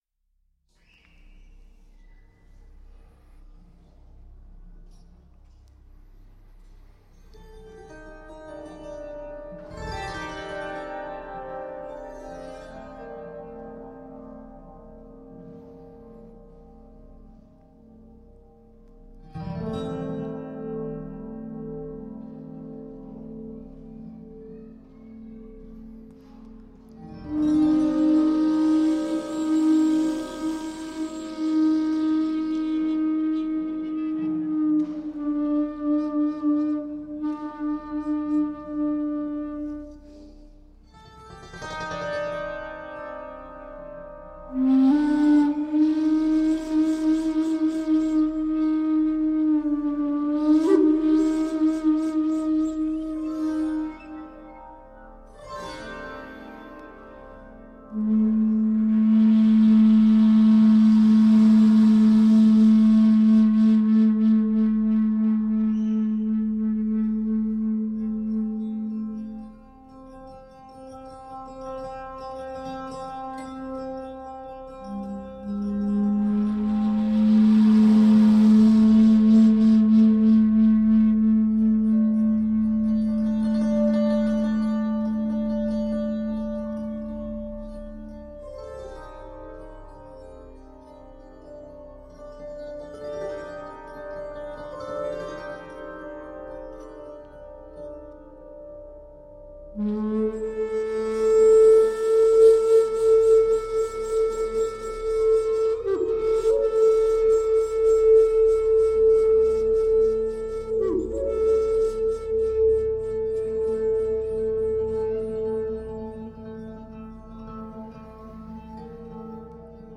Ambient, World, New Age, Space Music, Flute, Drone
japanese zenflute